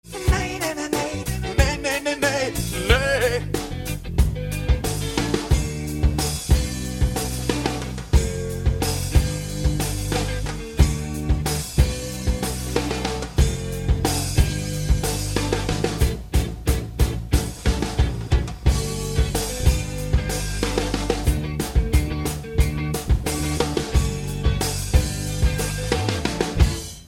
Nederlandstalige reggae.